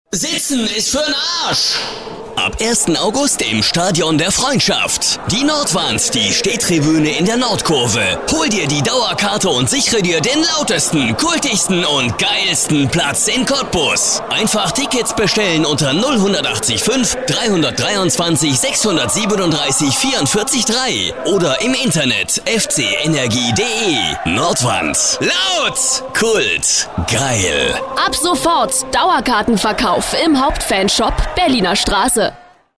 deutscher Sprecher.
Sprechprobe: Sonstiges (Muttersprache):
german voice over artist